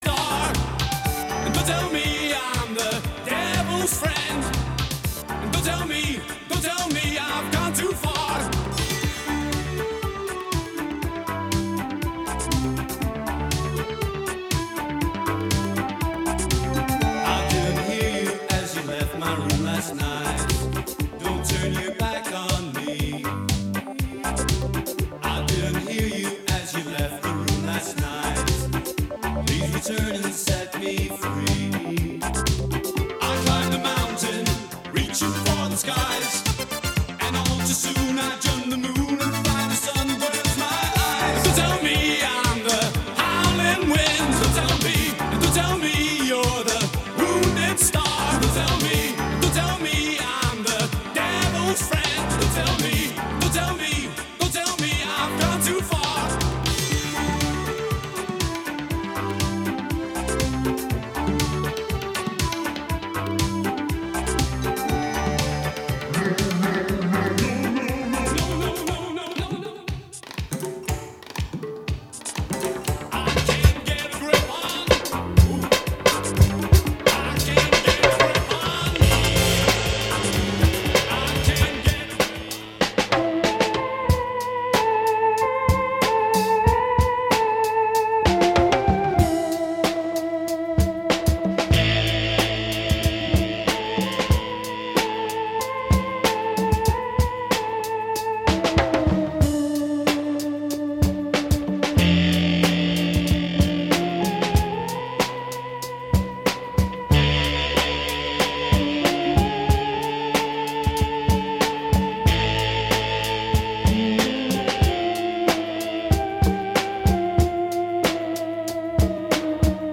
as well as introducing you to some you don't. New releases/sneak peeks/local artists/classics/b-sides/interviews with artists.